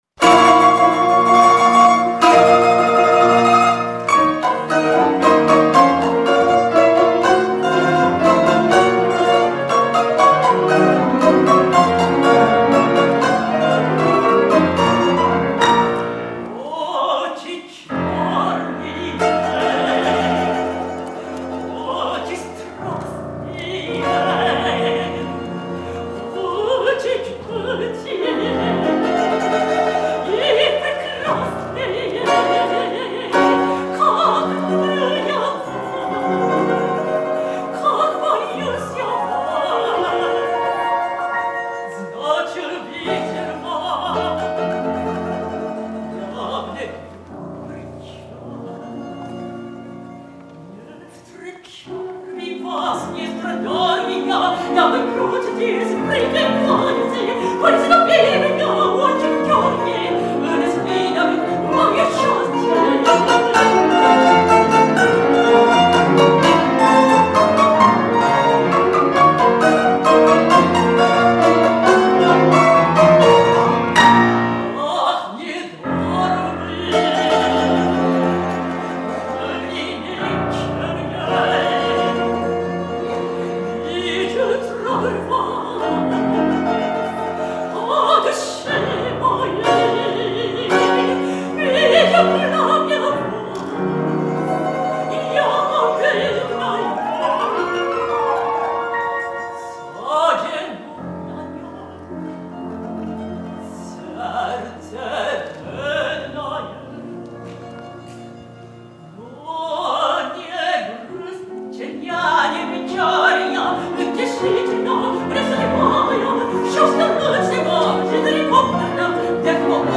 声楽家（メゾ・ソプラノ）
2009年7月23日　アラスカ公演